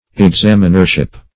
Examinership \Ex*am"in*er*ship\, n. The office or rank of an examiner.